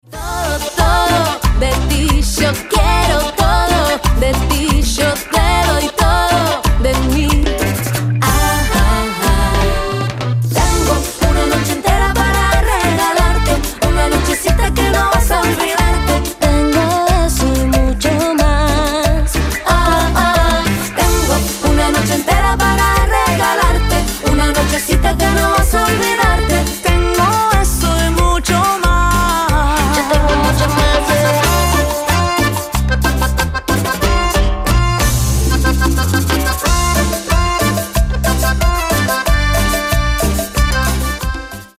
Чувственный испанский поп рингтон